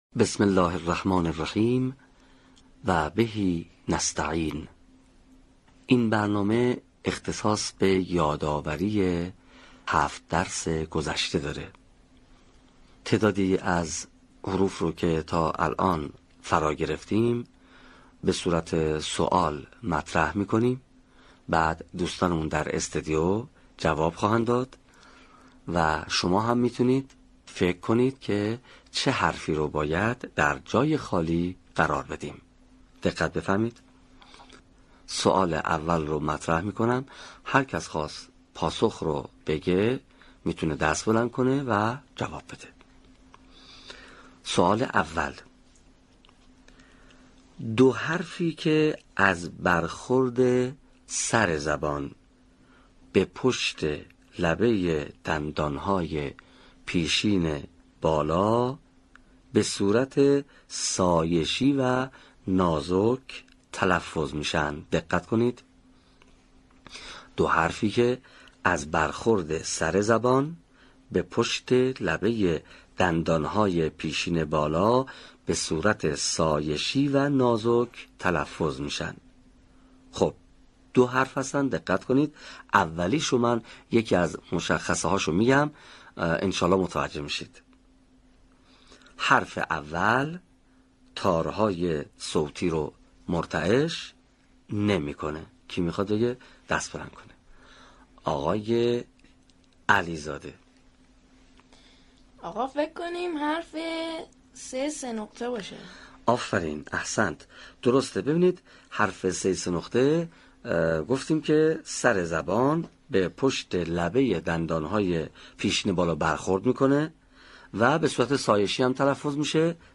صوت | مروری بر دروس تلفظ حروف